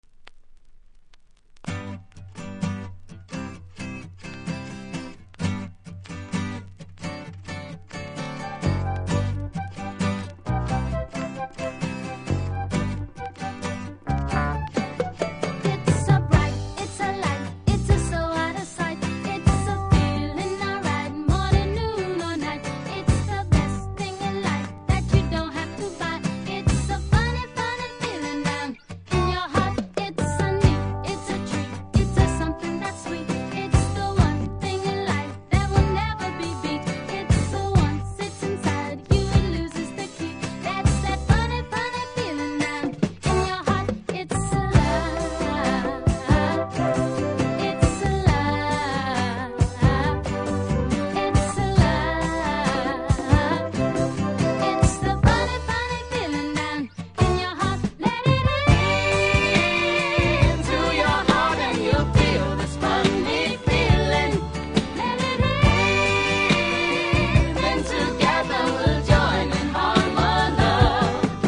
( Stereo )
見た目キズも少なく良好、多少ノイズ感じますがプレイは問題無いレベルなので試聴で確認下さい。